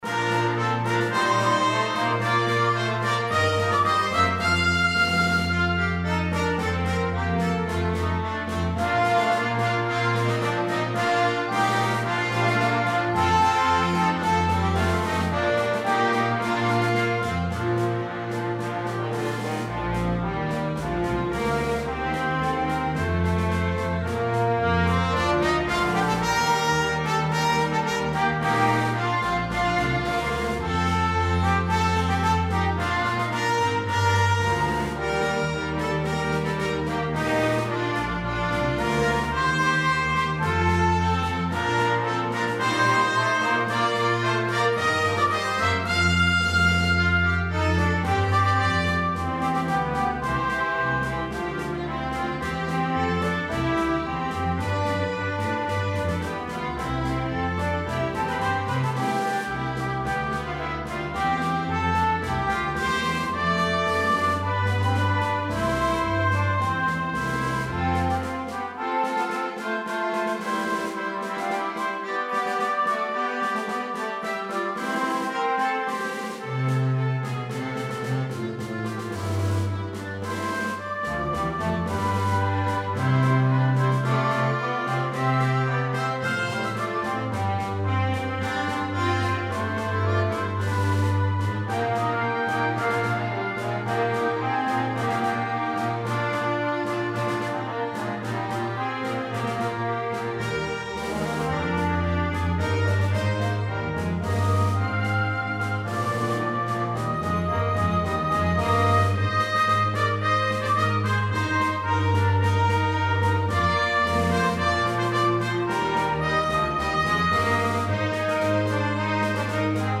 Arranged in 4 parts, fully orchestrated.